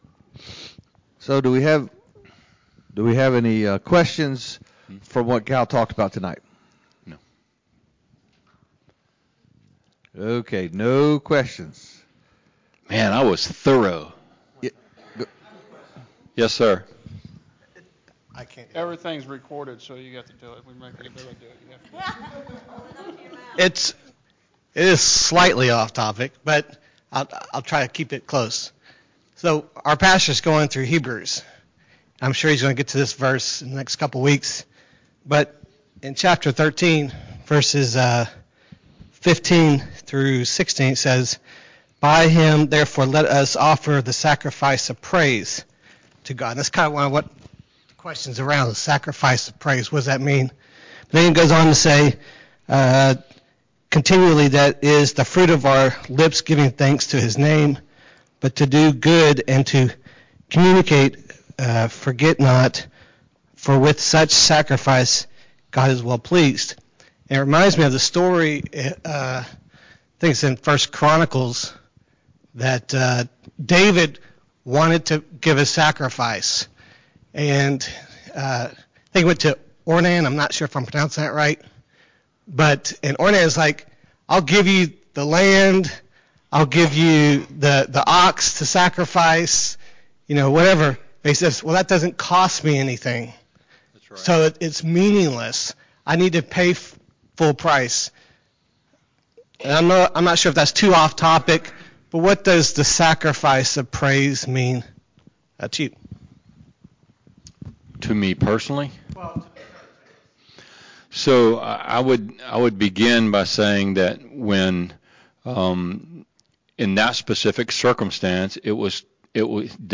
Sunday Night Teaching